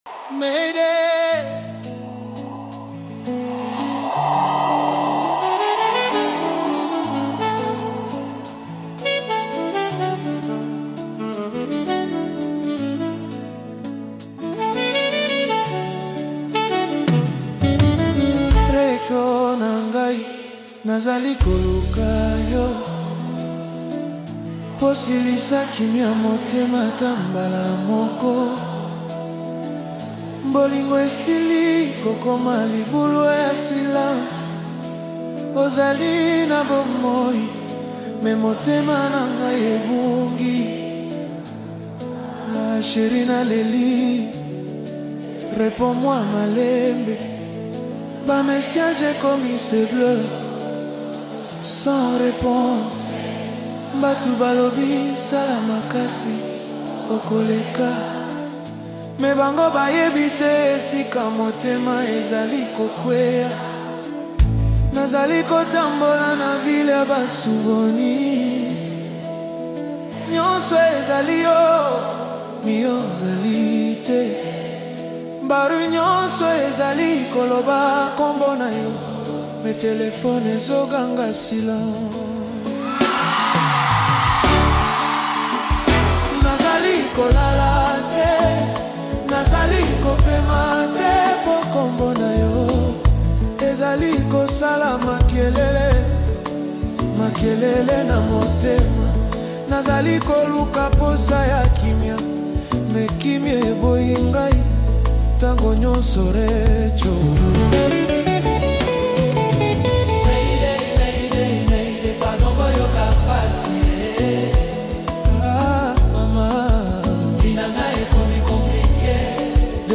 soul-stirring song